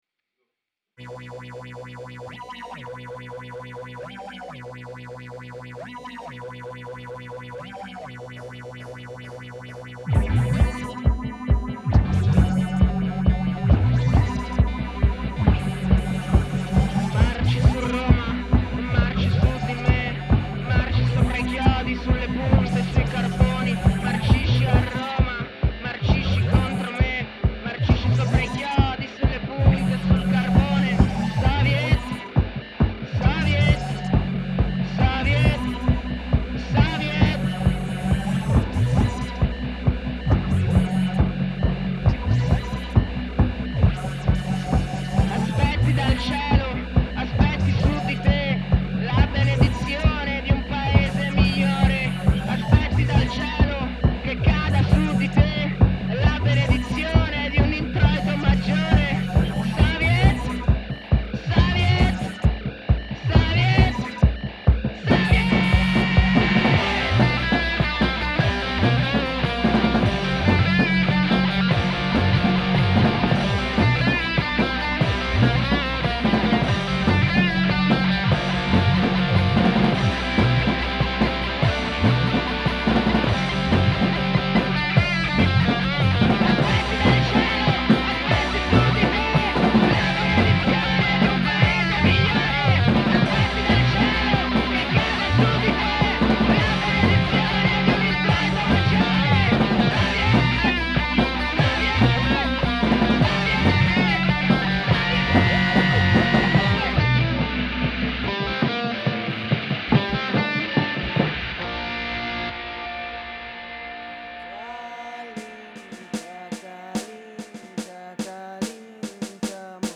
Chitarra
Voce, Synth
Tastiera, Synth
Batteria